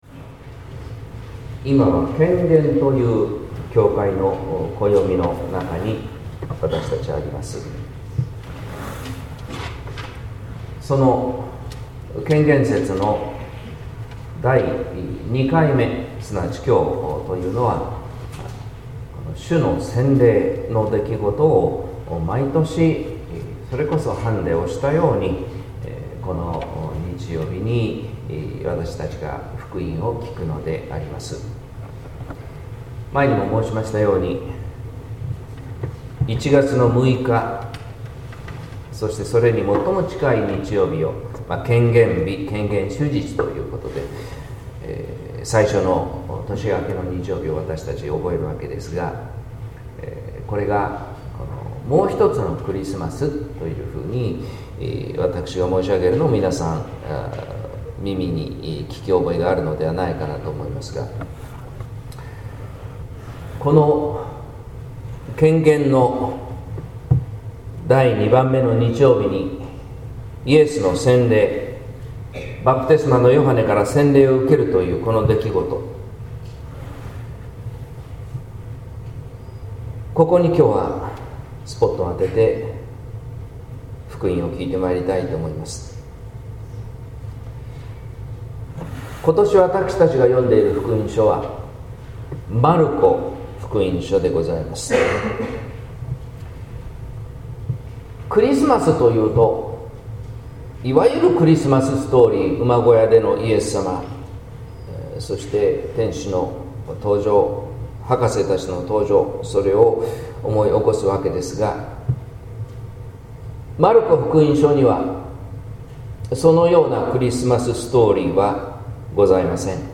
説教「愛と喜び、心のご飯」（音声版）